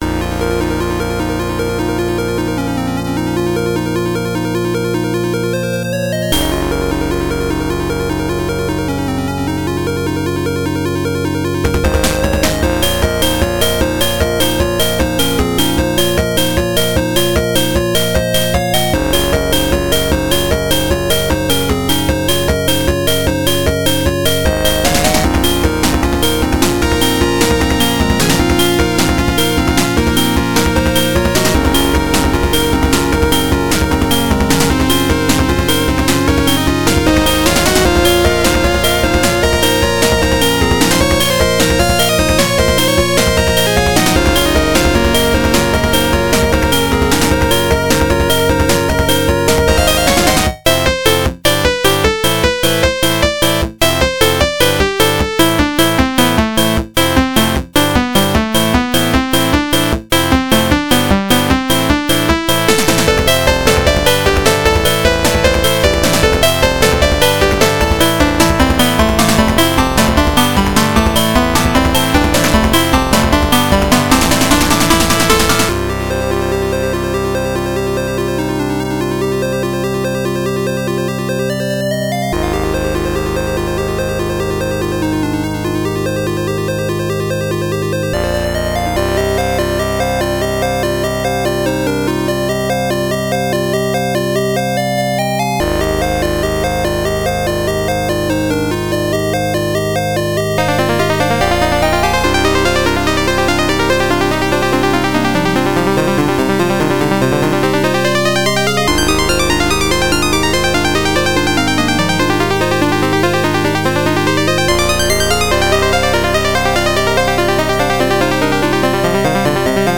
原游戏FM26K版，由PMDPlay导出。